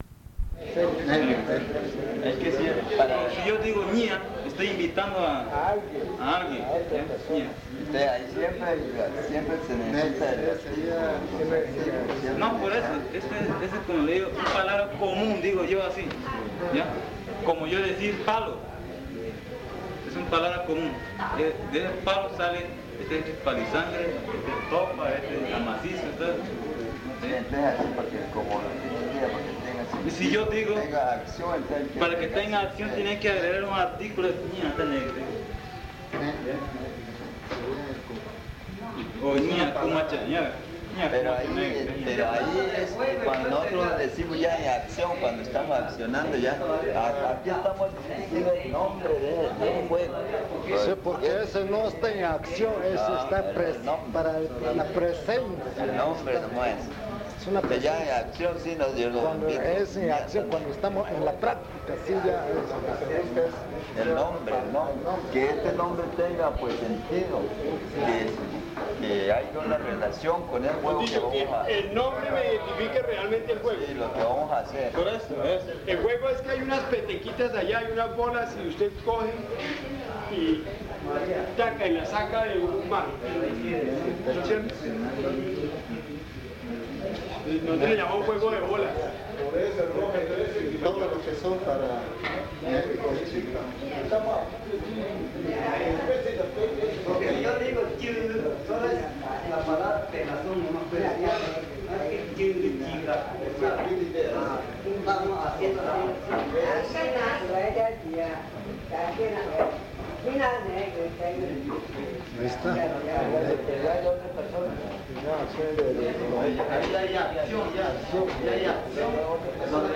Conversatorio sobre el taller
Tipisca, Amazonas (Colombia)